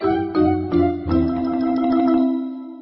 gameFailed.mp3